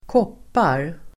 Uttal: [²k'åp:ar]